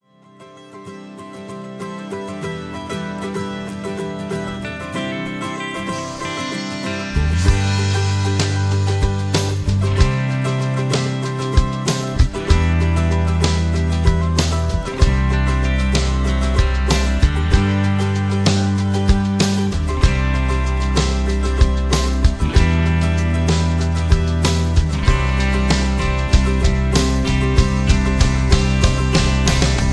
Key-G